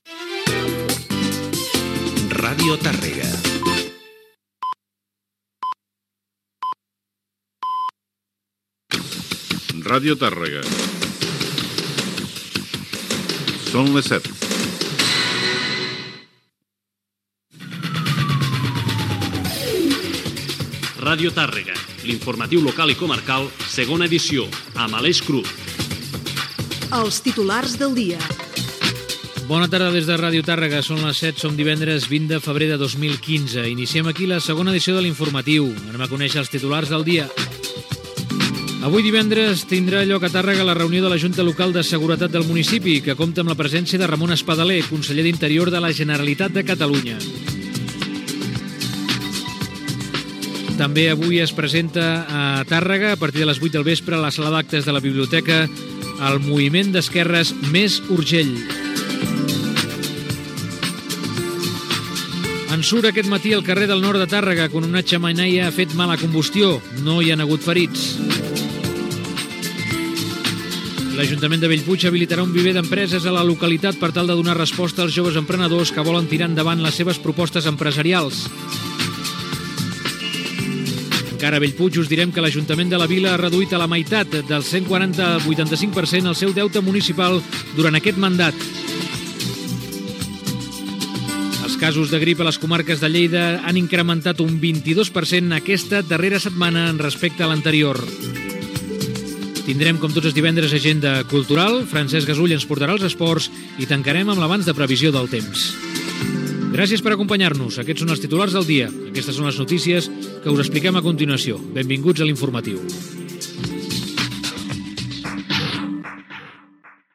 Indicatiu, hora, careta del programa i titulars Gènere radiofònic Informatiu
Banda FM